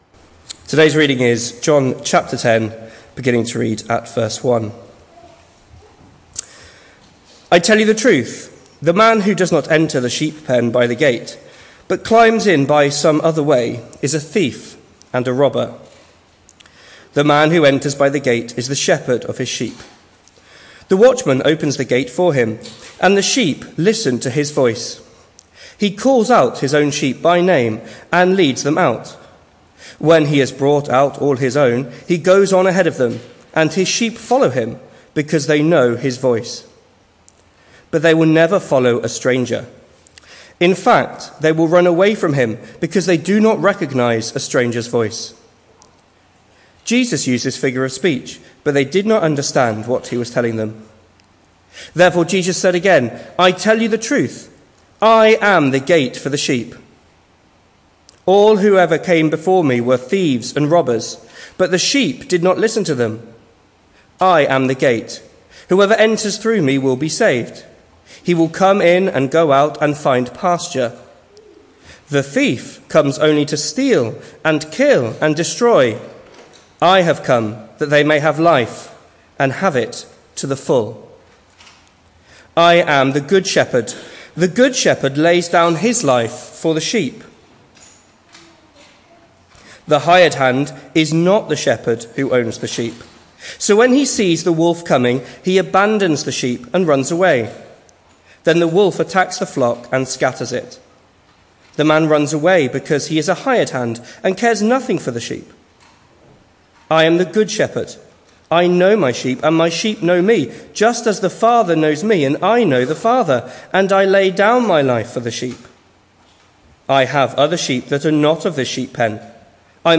The 13th of July saw us host our Sunday morning service from the church building, with a livestream available via Facebook.